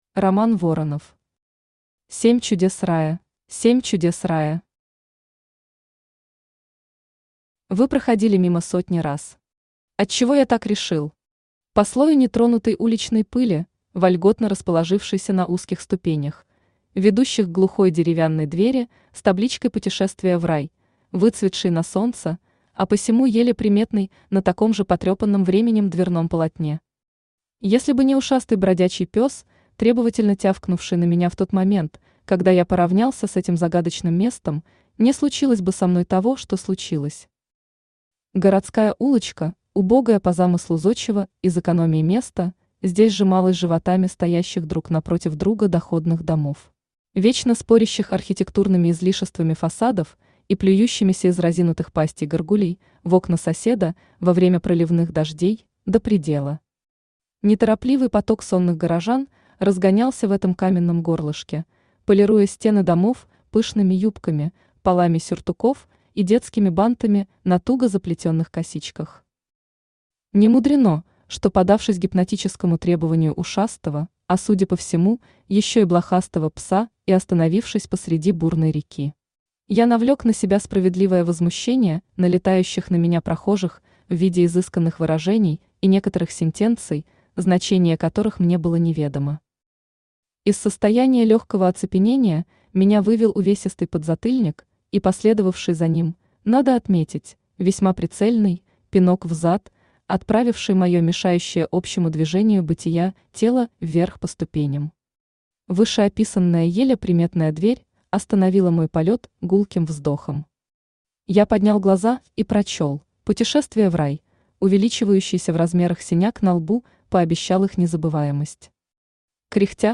Aудиокнига Семь Чудес Рая Автор Роман Воронов Читает аудиокнигу Авточтец ЛитРес.